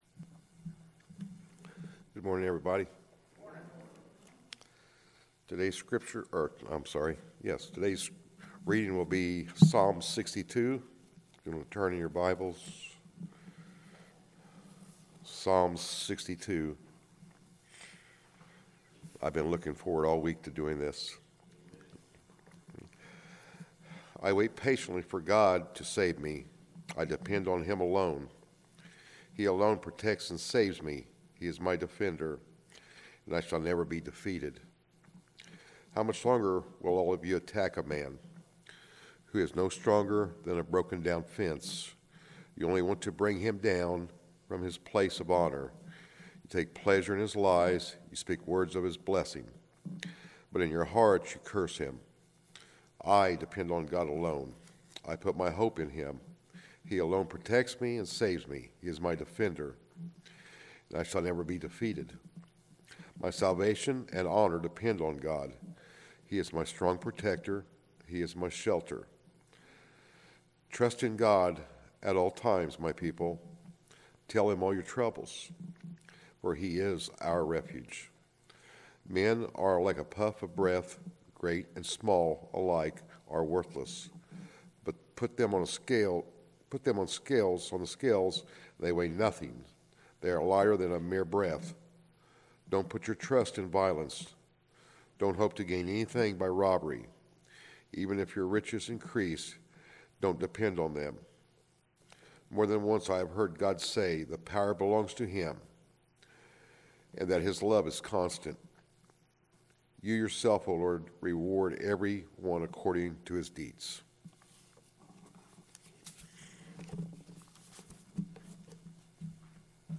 Sermons | Central Church of Christ